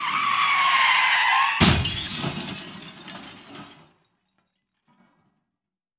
Car Crash Scene 3 Sound
transport
Car Crash Scene 3